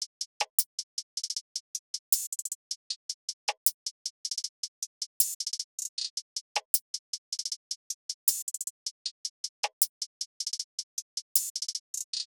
Cardiak_HiHat_Loop_1_156bpm.wav